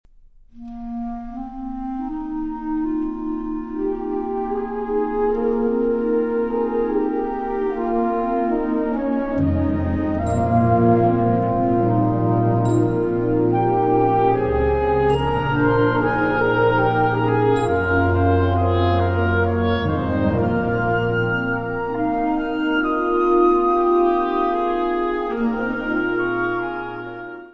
Besetzung Ha (Blasorchester)